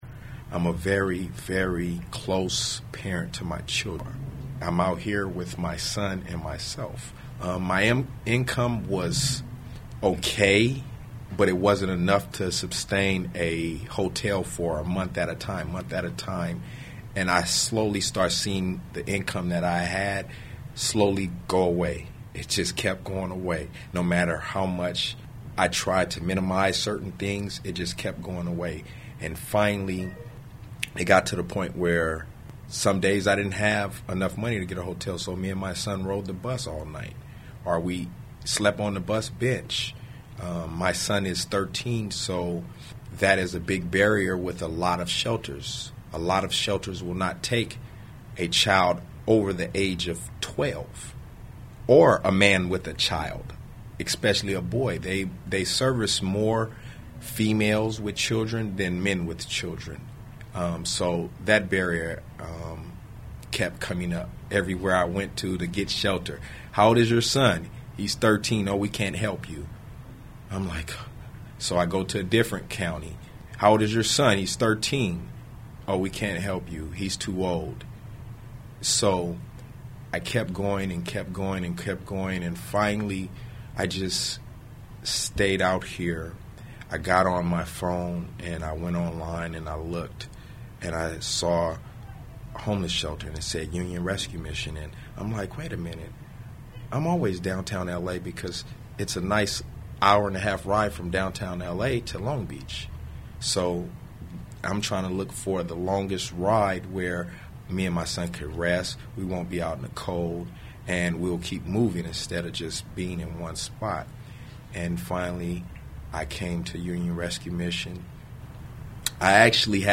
An Interview with a Homeless Single Los Angeles Father